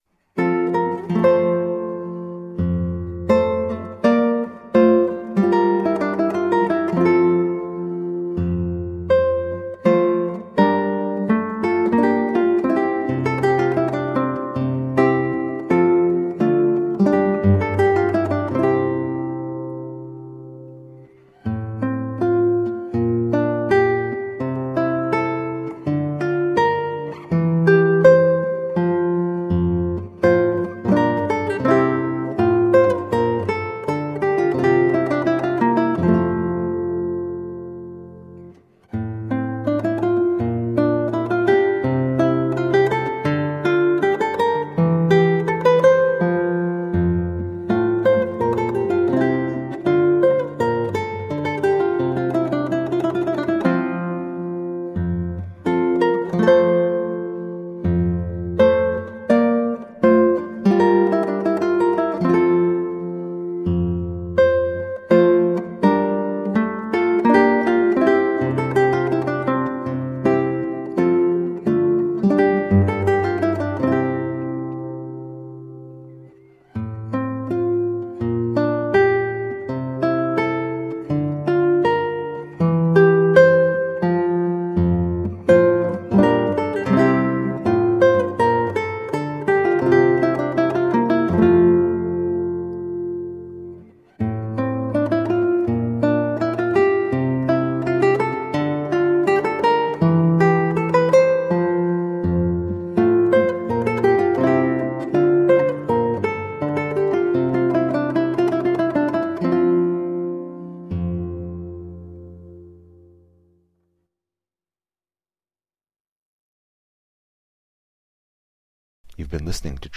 Colorful classical guitar.
played on 8-string guitar